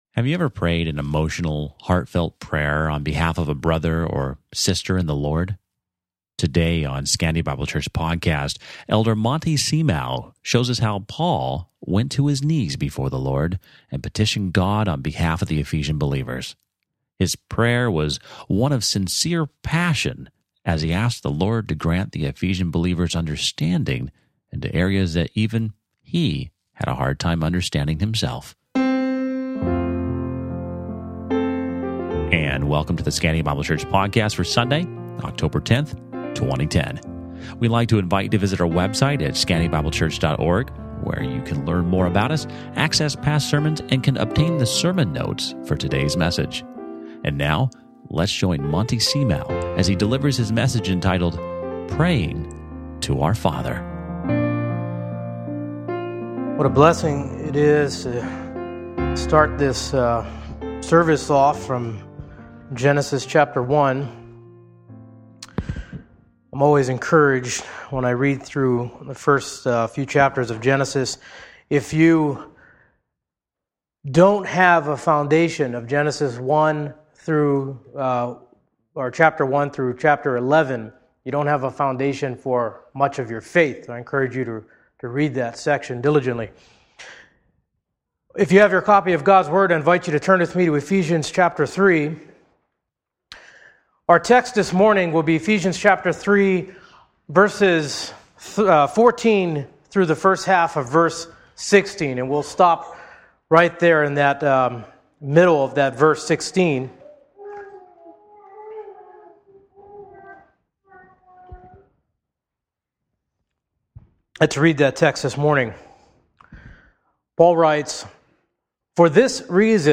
Listen to Sermon Only
Date: 04/12/2020, Easter Sunday